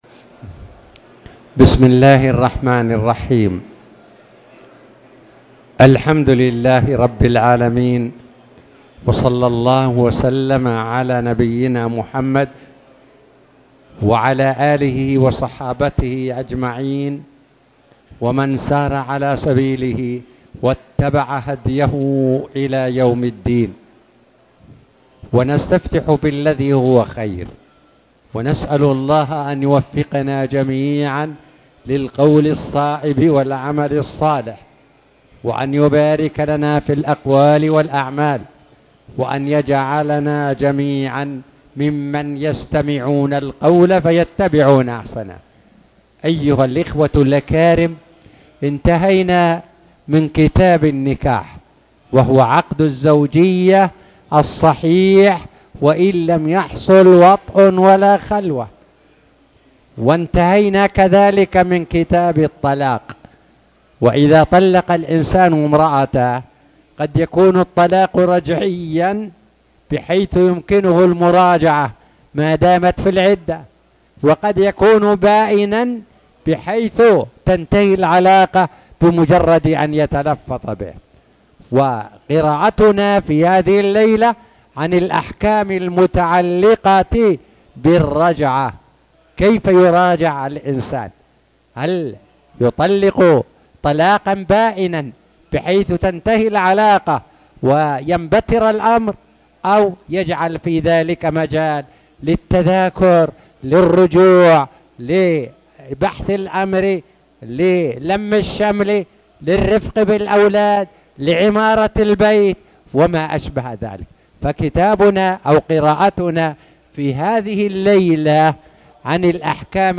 تاريخ النشر ٢٩ رجب ١٤٣٨ هـ المكان: المسجد الحرام الشيخ